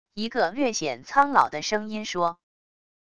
一个略显苍老的声音说wav音频